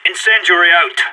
CSGO Incendiary Out Sound Effect Free Download